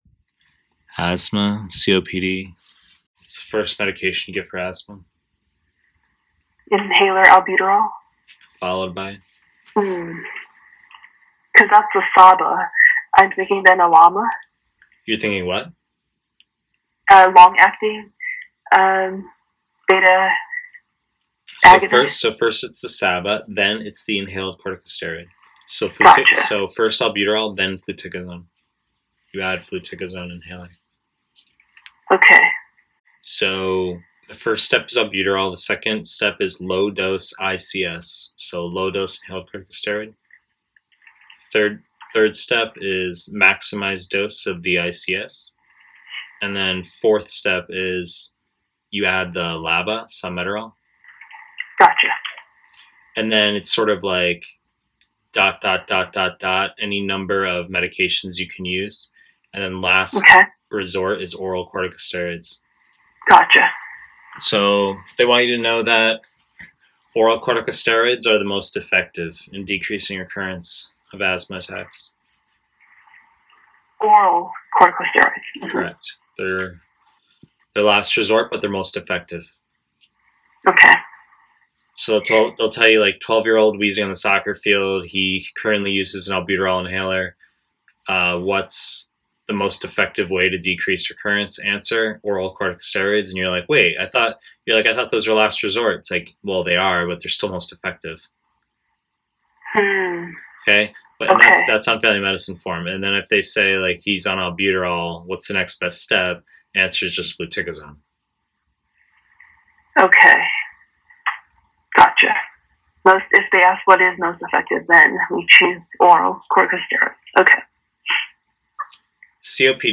Family medicine / Pre-recorded lectures